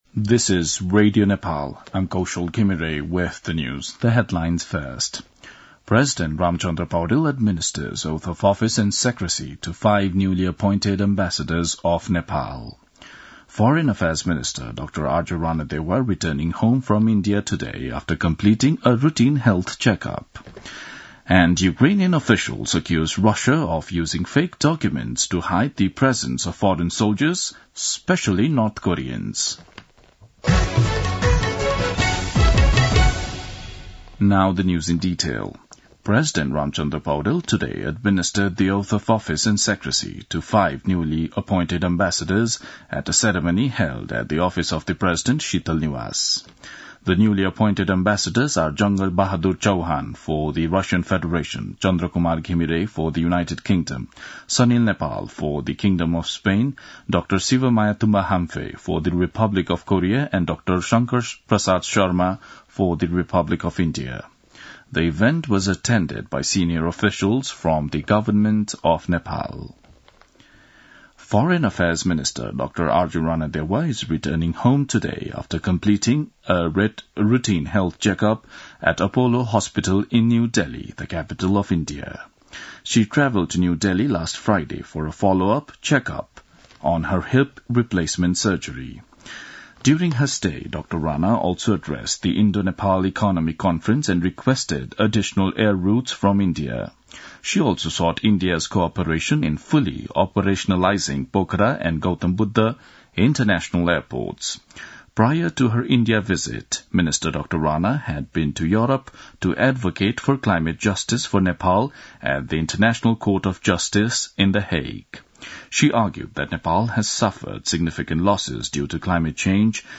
दिउँसो २ बजेको अङ्ग्रेजी समाचार : ९ पुष , २०८१
2-pm-english-news-1-15.mp3